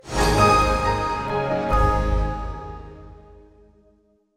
This is now my Windows startup sound.